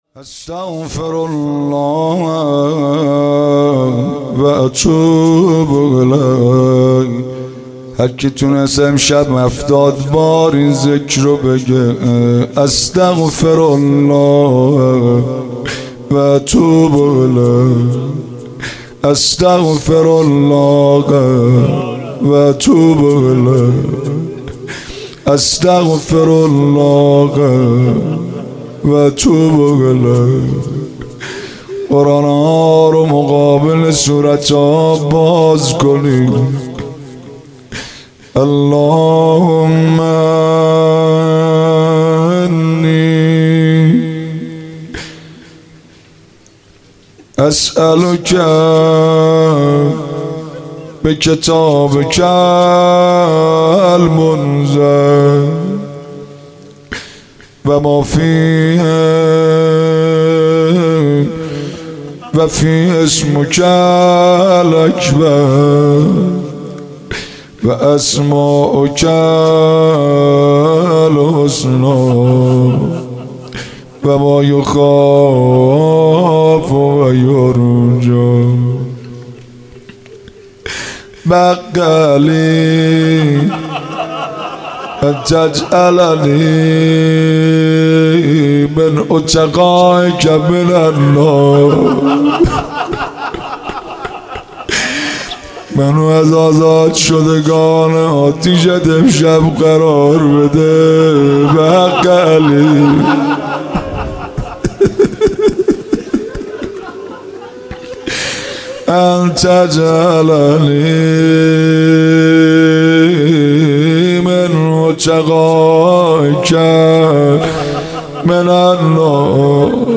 مراسم احیا
مناجات